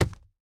Minecraft Version Minecraft Version snapshot Latest Release | Latest Snapshot snapshot / assets / minecraft / sounds / block / chiseled_bookshelf / insert2.ogg Compare With Compare With Latest Release | Latest Snapshot